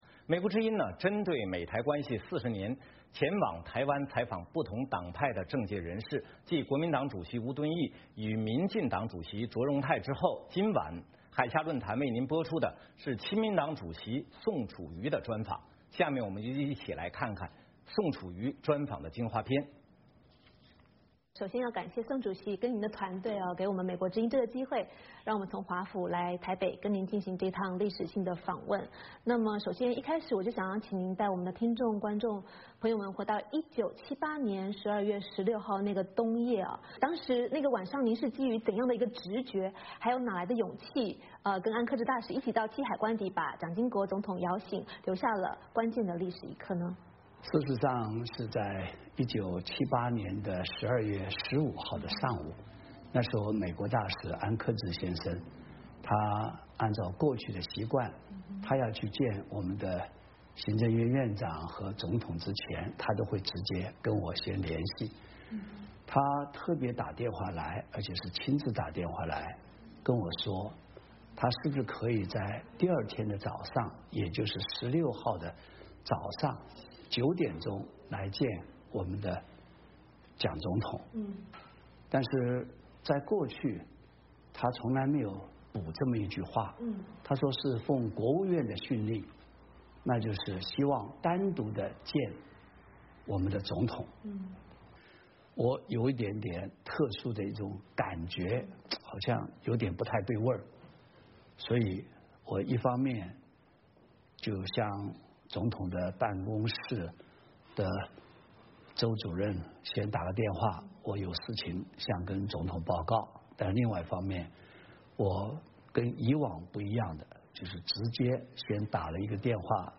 《海峡论谈》专访亲民党主席宋楚瑜